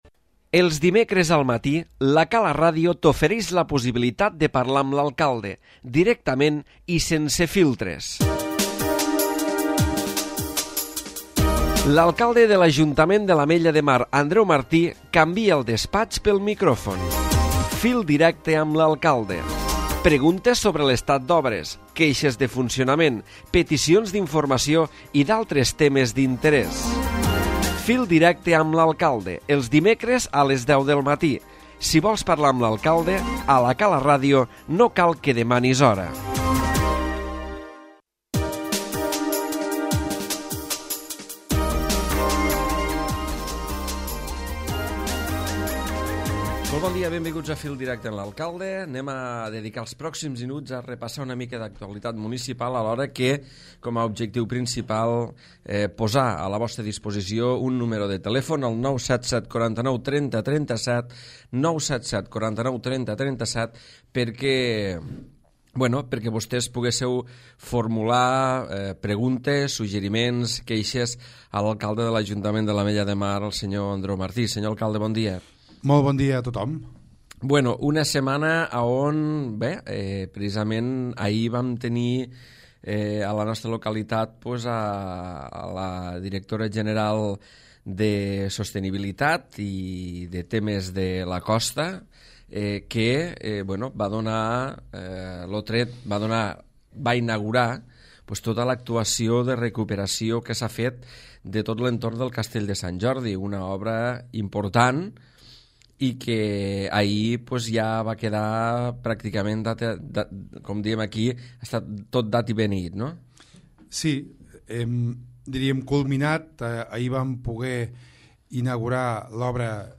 Mar, 2010 a les 10:00 per admin a Fil directe 1992 reproduccions Andreu Martí, Alcalde de l'Ajuntament de l'Ametlla de Mar, a parlat avui al Fil Directe de la inauguració ahir de la recuperació paisatgística de l'entorn del Castell de Sant Jordi. A més s'ha referit a l'inici després de Setmana Santa de les obres d'enderroc de l'antiga fabrica de gel i per últim de les gestions que es fan en suport al sector de la tonyina del port de l'Ametlla de Mar.